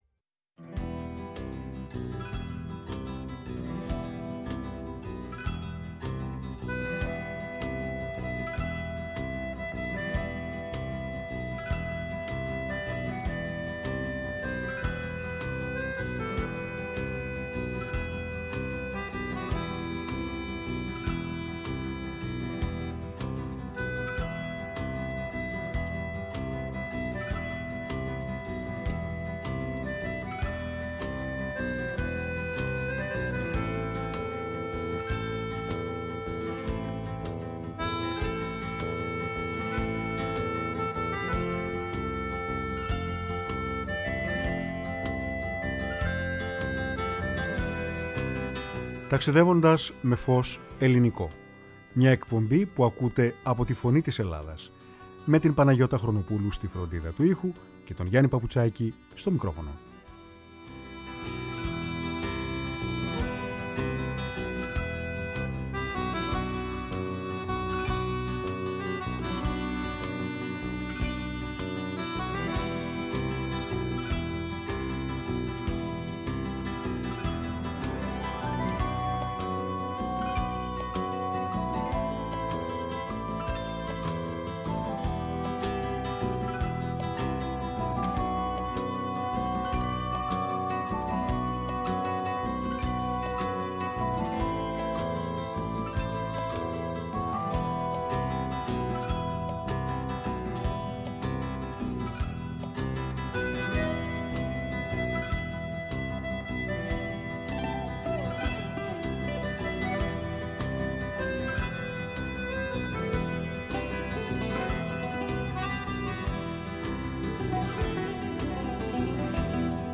Στην εκπομπή ακούστηκαν μουσικές και τραγούδια από τον σύγχρονο ελληνικό κινηματογράφο!
Συνεντεύξεις